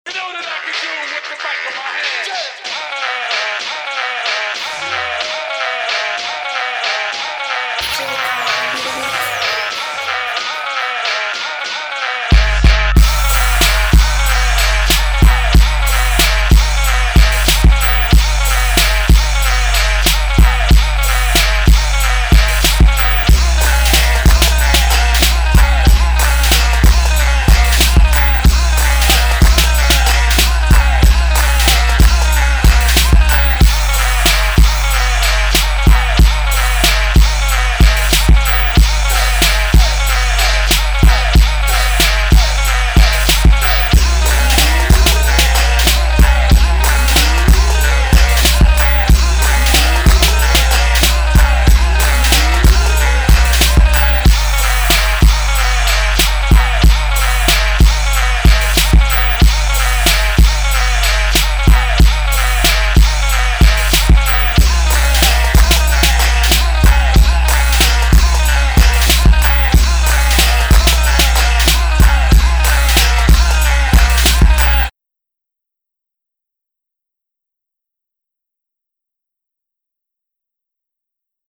Here's the official instrumental
Rap Instrumentals